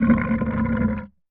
Sfx_creature_spikeytrap_idle_os_02.ogg